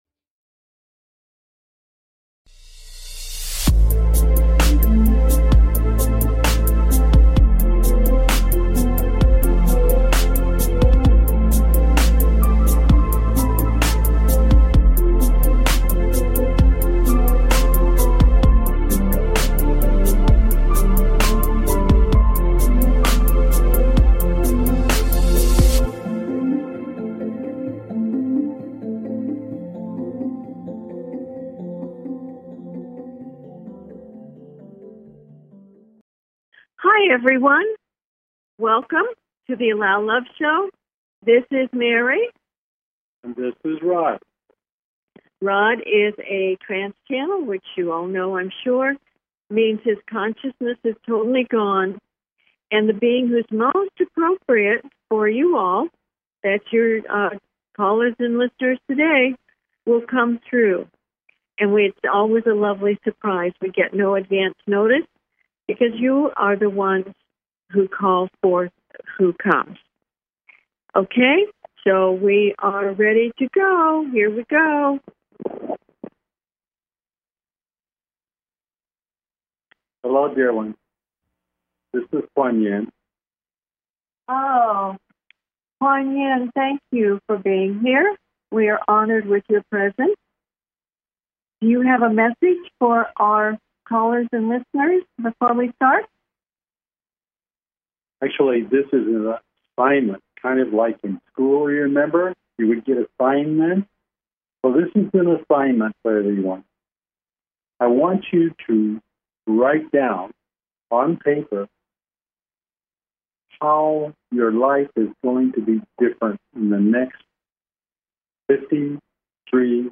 Talk Show Episode
Their purpose is to provide answers to callers’ questions and to facilitate advice as callers request.